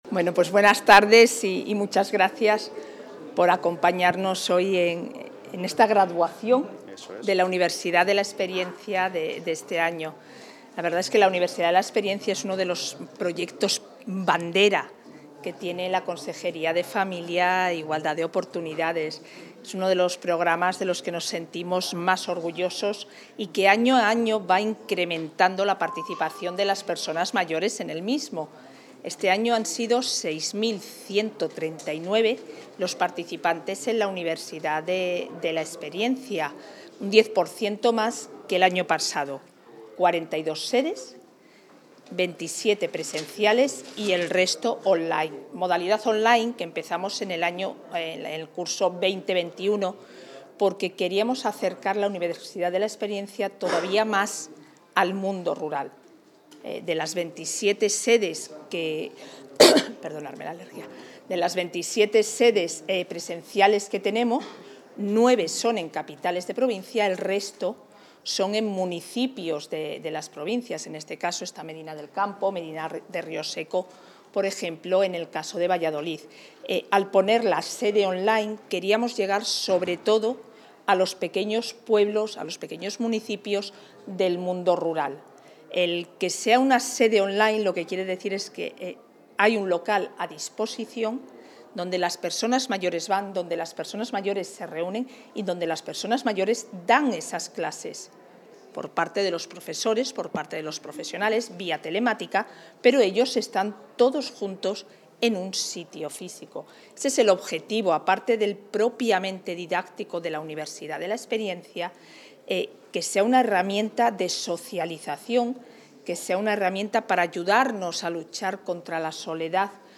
Intervención de la vicepresidenta.
La vicepresidenta y consejera de Familia e Igualdad de Oportunidades, Isabel Blanco, ha amadrinado a los graduados del curso 2024-2025 vinculados a la Universidad Europea Miguel de Cervantes, donde ha anunciado que crecerá un 10 % la financiación del programa, se crearán 600 plazas y se incorporarán tres nuevas sedes online.